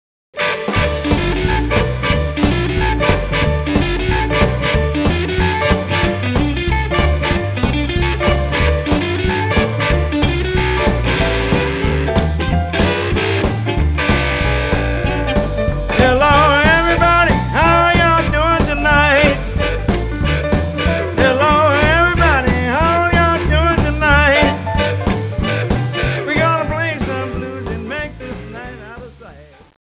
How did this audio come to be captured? at Liquid, Toronto